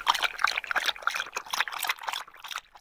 petdrink.wav